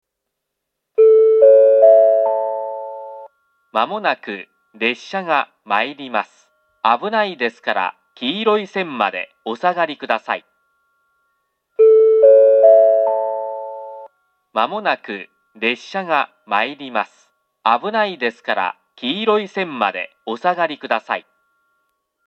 ２番線接近放送 交換がある場合のみ使用するホームです。